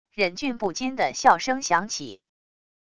忍俊不禁的笑声响起wav音频